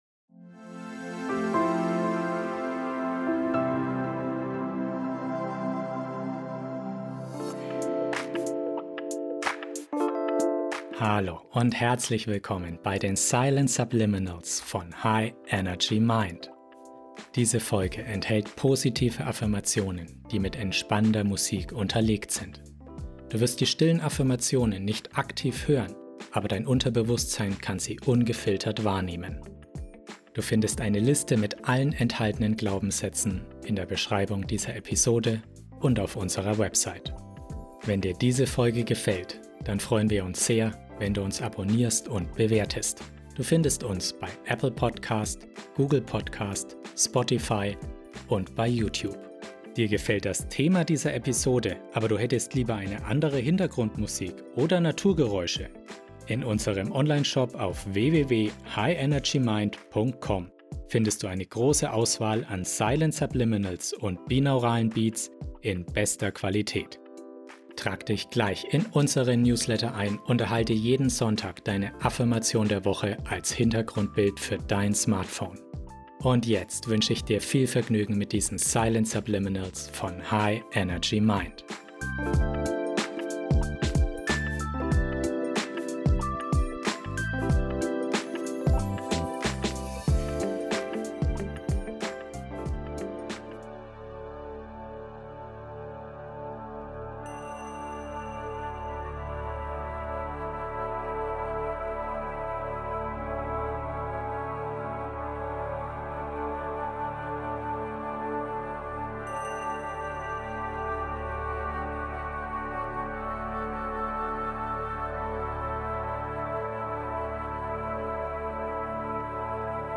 Über diese Folge In dieser tiefgründigen Folge im Silent Subliminals Podcast erfährst du, wie du die Heilung deines inneren Kindes angehen kannst. Die beruhigende Musik in 432 Hz begleitet kraftvolle Silent Subliminals, die dein Unterbewusstsein erreichen und dir helfen, alte Wunden zu heilen.